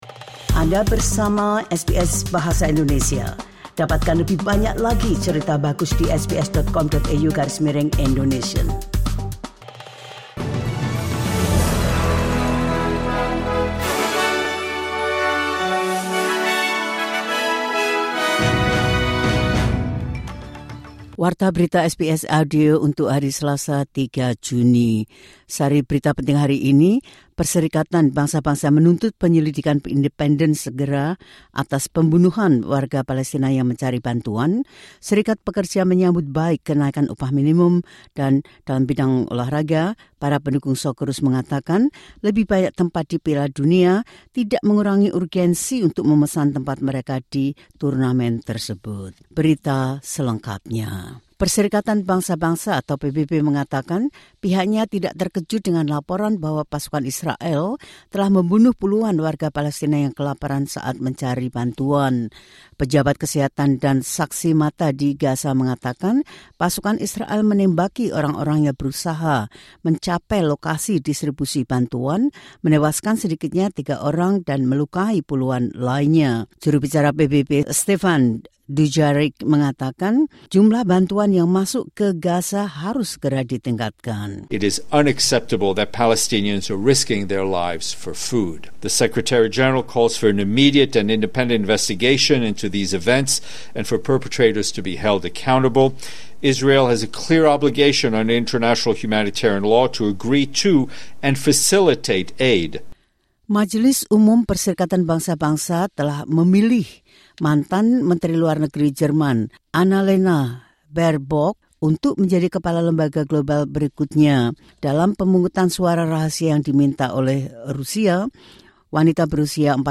The latest news SBS Audio Indonesian Program – 03 Jun 2025.